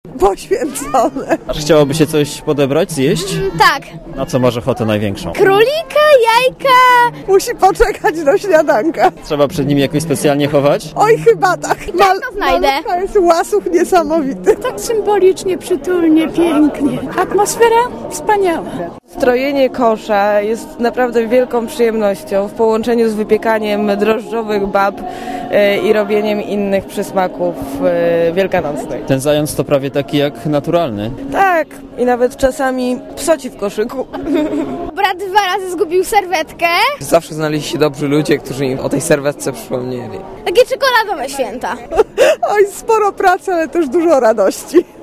Tłum mieszkańców Krakowa z koszami pełnymi